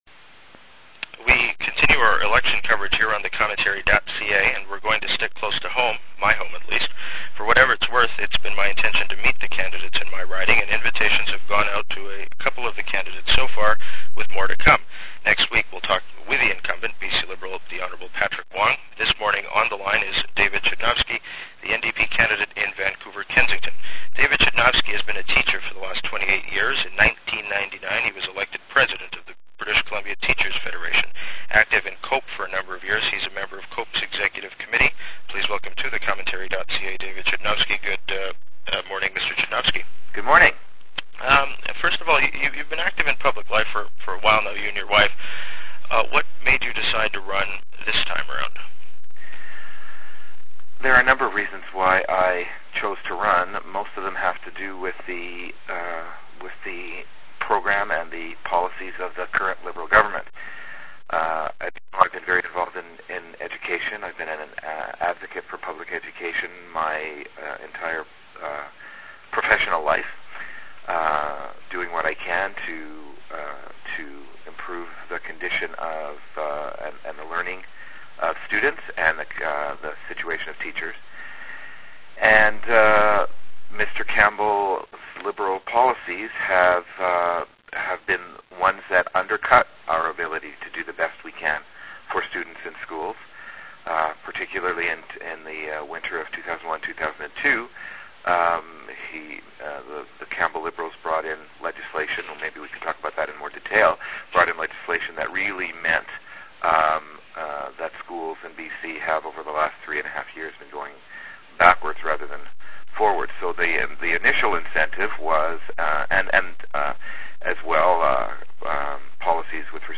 This morning, on the line is David Chudnovsky, the NDP candidate in Vancouver-Kensington.